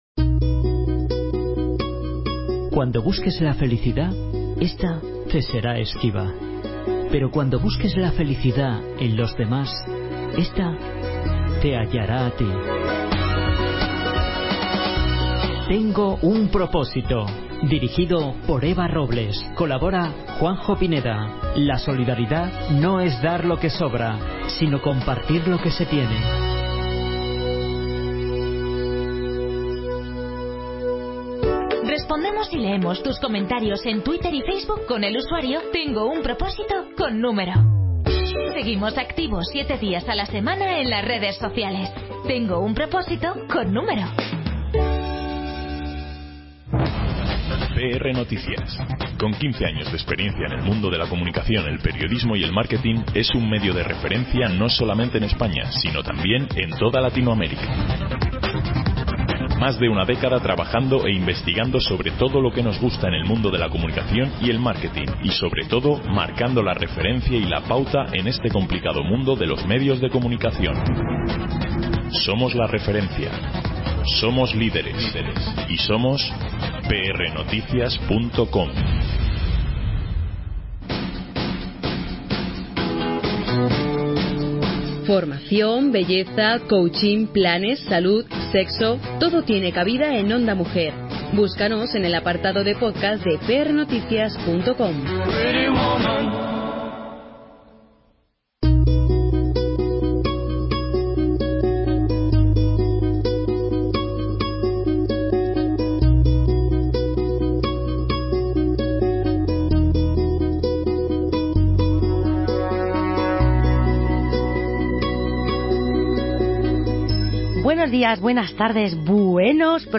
La agenda solidaria semanal da visibilidad a los eventos de esta semana; las entrevistas a agentes solidarios de instituciones, tanto privadas como públicas y reportajes en profundidad sobre Fundaciones o Asociaciones sin ánimo de lucro involucradas en la defensa de los Derechos Humanos Fundamentales, las que trabajan a favor d